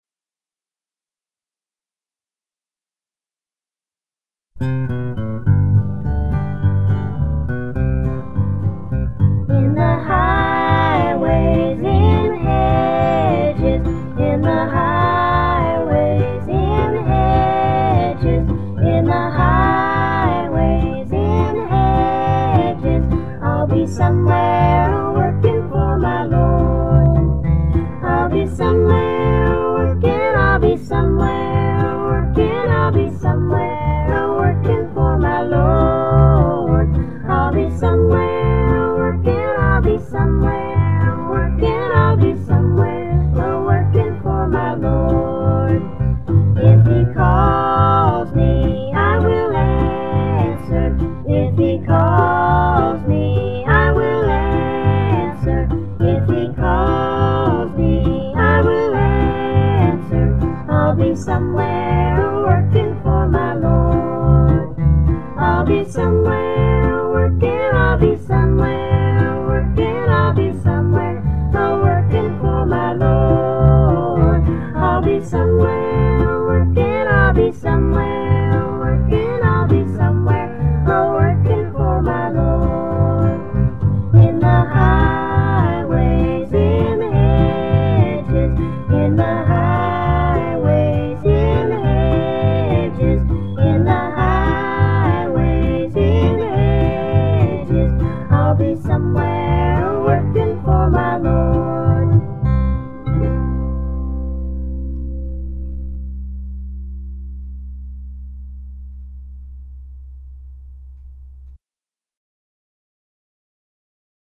all gospel
bass, vocals